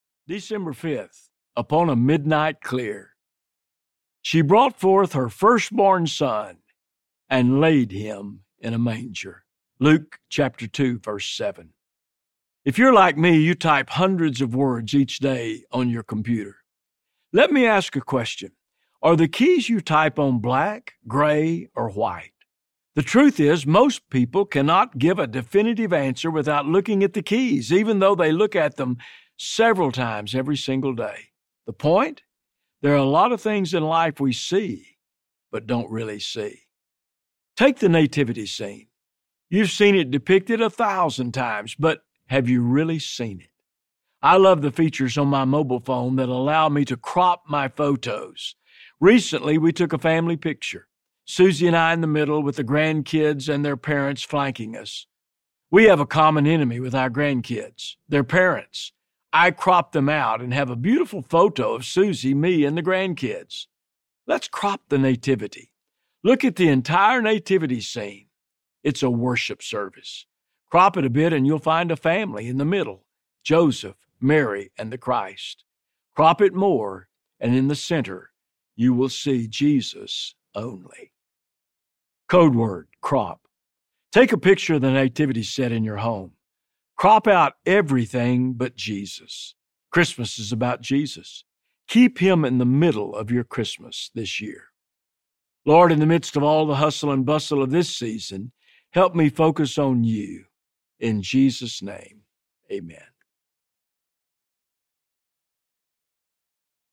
The Christmas Code Booklet Audiobook